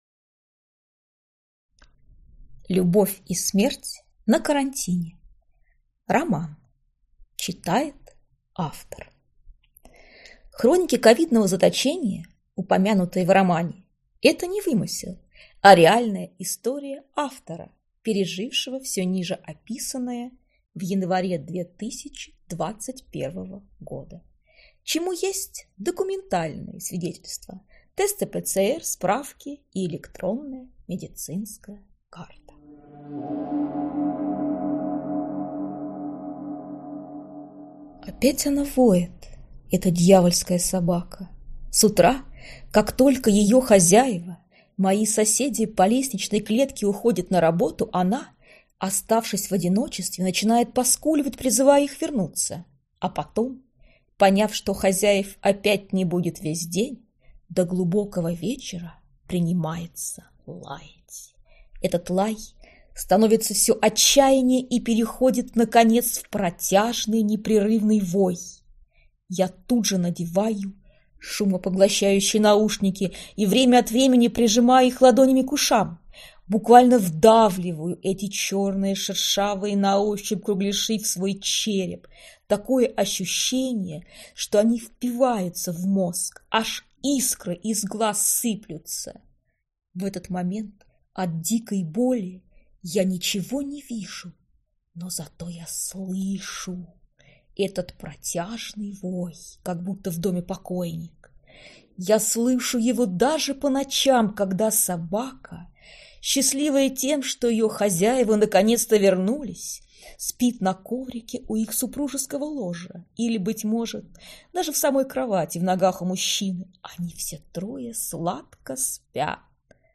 Аудиокнига Любовь и смерть на карантине | Библиотека аудиокниг